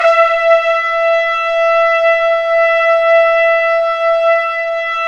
Index of /90_sSampleCDs/Roland LCDP06 Brass Sections/BRS_Tpts mp)f/BRS_Tps Velo-Xfd